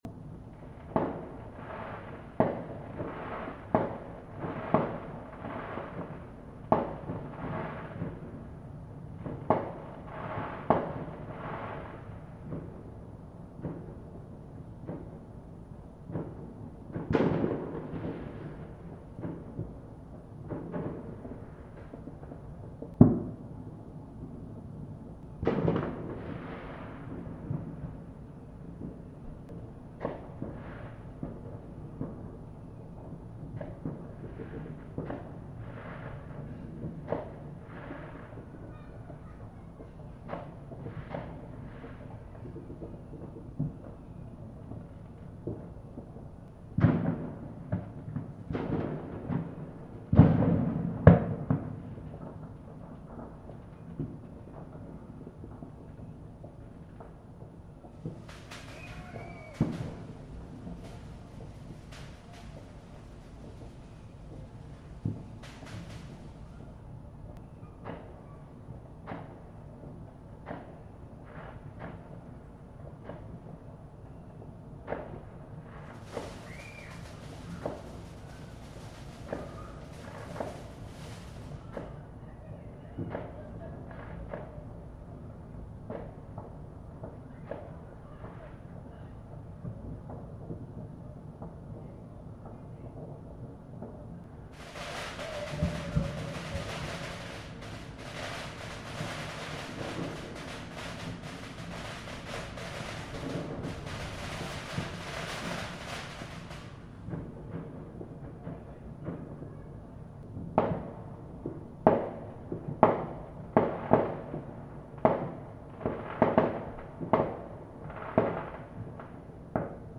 Bonfire Night Fireworks 5 Nov 2021
Here are some highlights from the various fireworks I could hear from my West London window on Bonfire Night. Some are suddenly really loud so may make you jump. Use good headphones or speakers for best stereo sound.